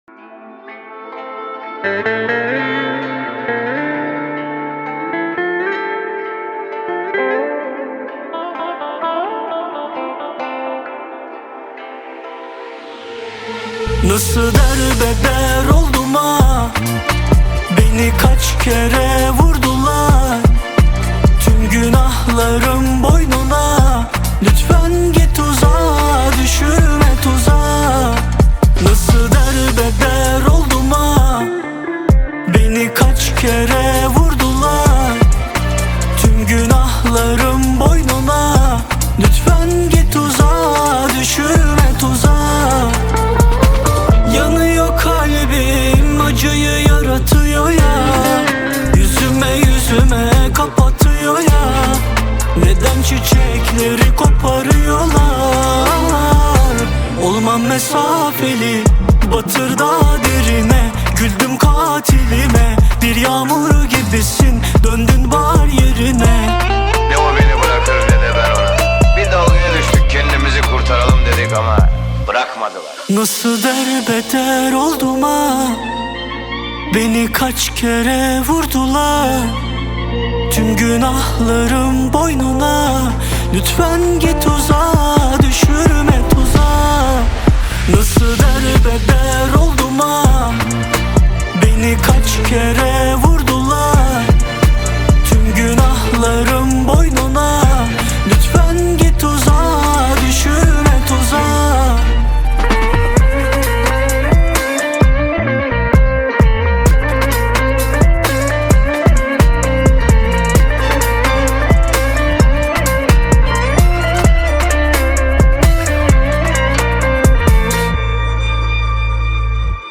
Turki Muzik